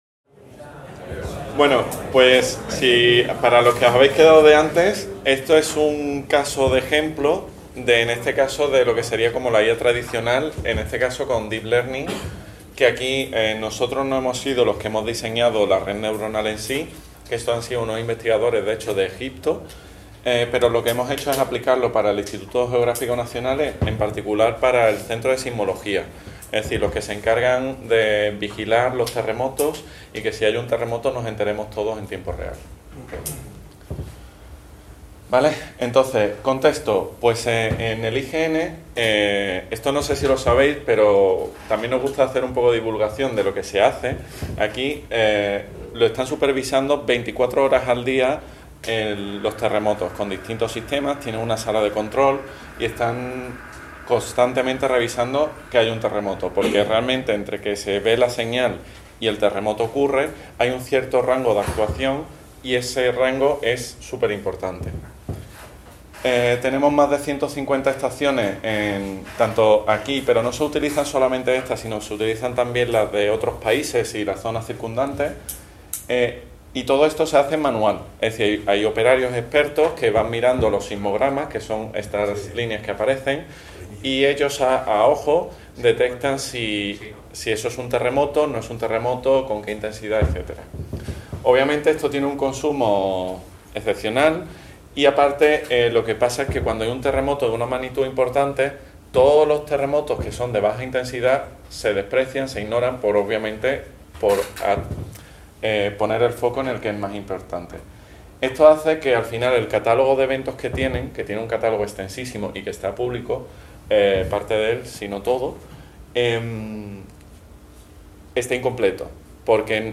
en el marc de les 18enes Jornades de SIG Lliure 2025 organitzades pel SIGTE de la Universitat de Girona. Es presenten les eines d'intel·ligència artificials i dades espacials que han permès detectar més ràpidament els moviments sísmics  Aquest document està subjecte a una llicència Creative Commons: Reconeixement – No comercial – Compartir igual (by-nc-sa) Mostra el registre complet de l'element